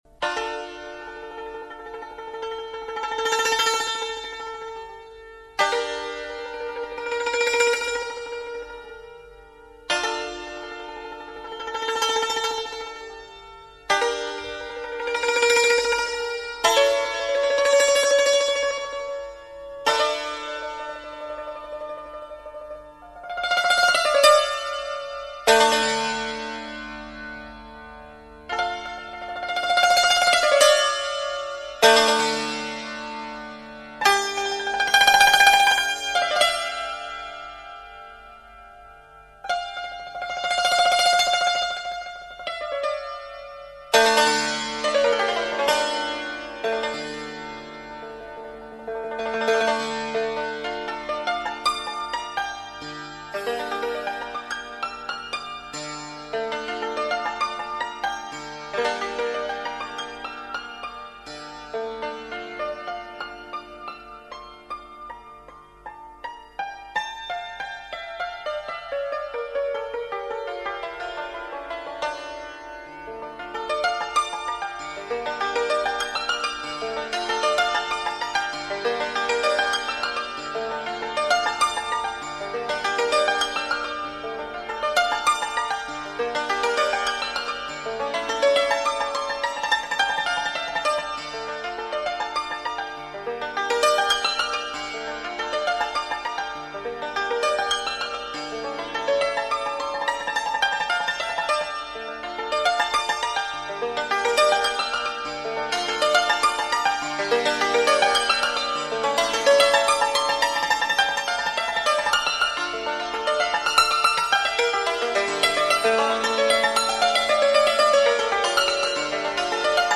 سنتور نوازی